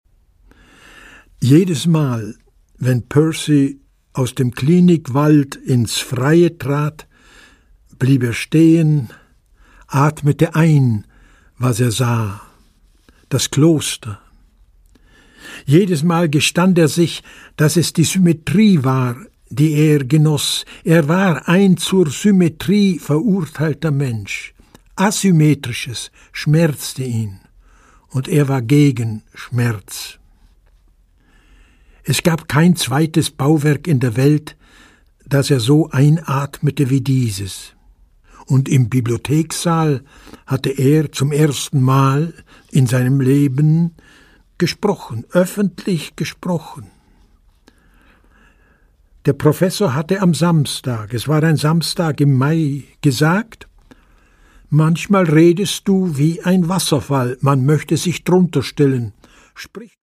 Produkttyp: Hörbuch-Download
Gelesen von: Martin Walser